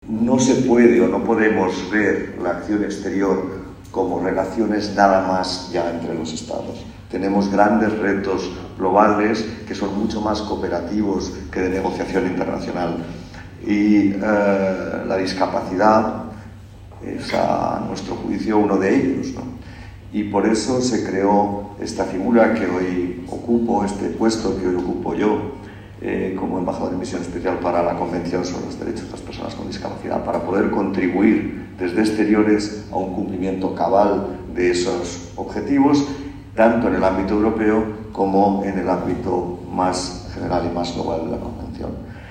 Embajadores, cónsules y consejeros de embajadas participaron en la sede ‘Por Talento Digital’, de Fundación ONCE, en el VII Encuentro Diplomacia para la Inclusión organizado por el Grupo Social ONCE y la Academia de la Diplomacia, bajo el patrocinio del embajador de la República Checa en nuestro país, que ostenta la presidencia semestral del Consejo de la UE.